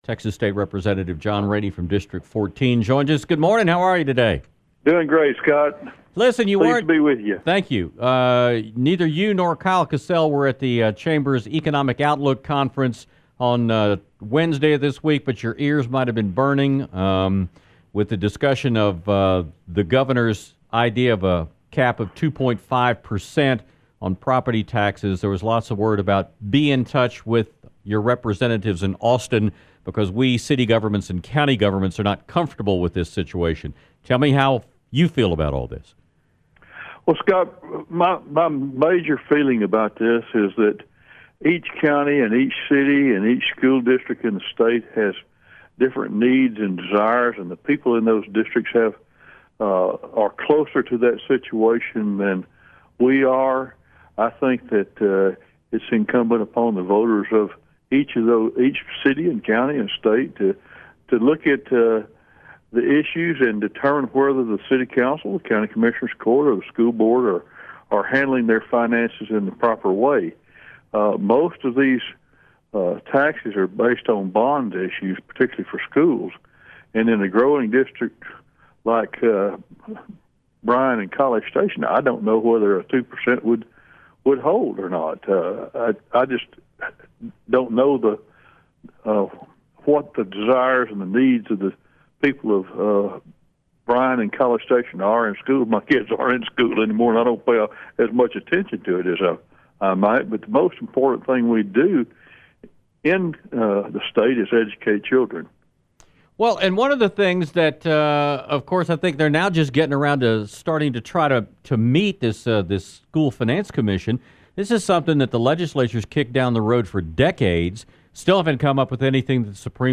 District 14 State Representative John Raney discussed his feelings about the governor’s proposal of a property tax cap, funding public education, the governor’s stance on education issues, and more during his appearance on The Infomaniacs on Friday, January 26, 2018.